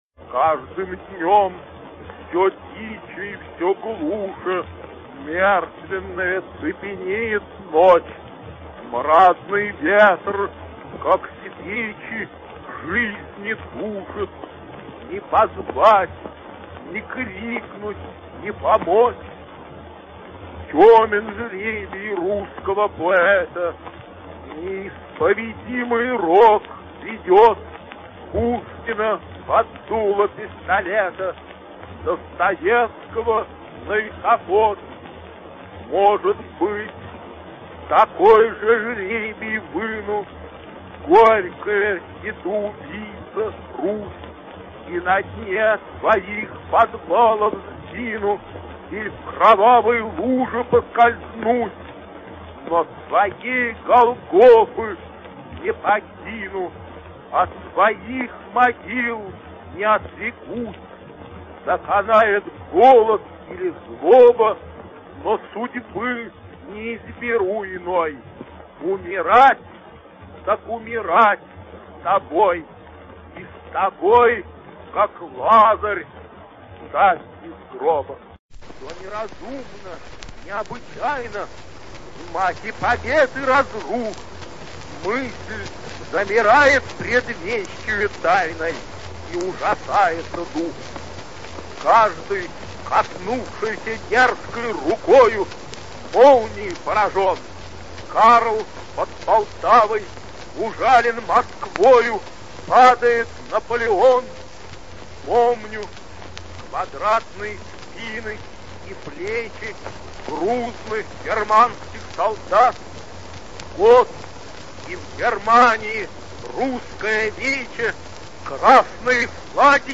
Максимилиан Александрович Волошин читает два своих стихотворения. Запись 1924 года.
voloshin-chitaet-svoi-stihi.mp3